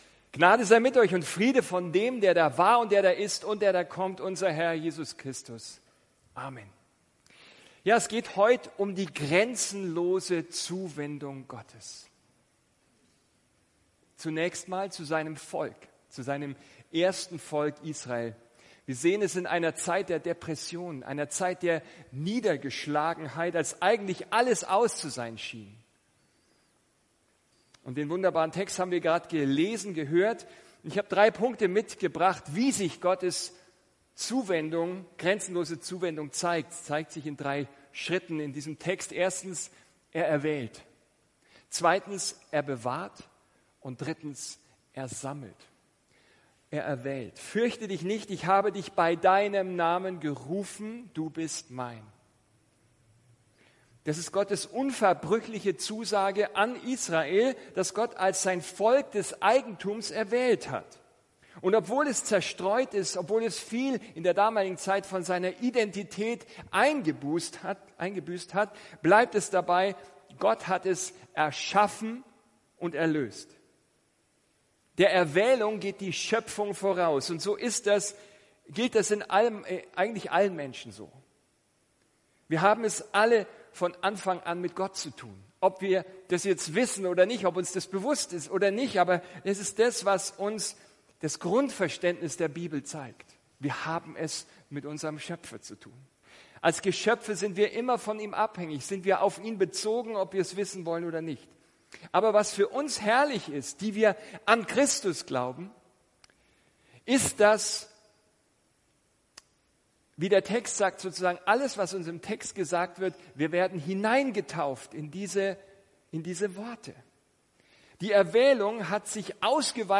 Ein Studienblatt zur Predigt ist im Ordner “Notizen” verfügbar